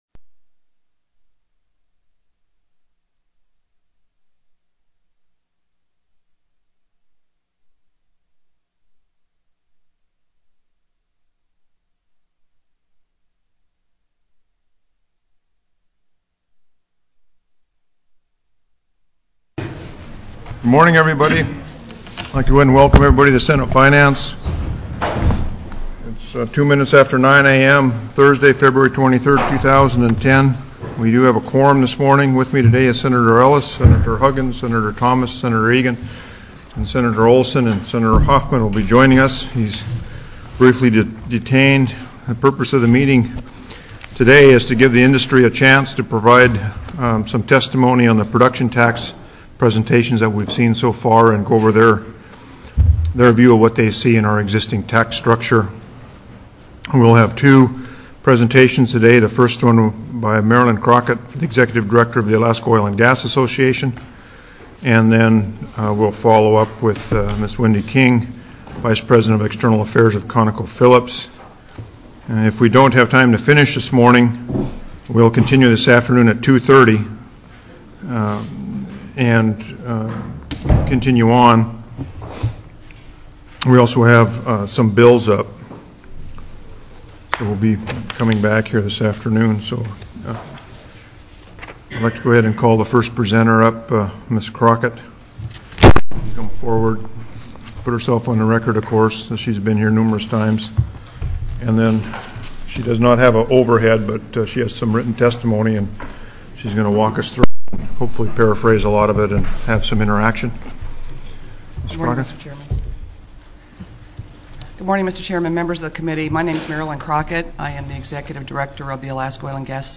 Industry Testimony - Oil & Gas Tax Review
Co-Chair Stedman called the Senate Finance Committee meeting to order at 9:03 a.m.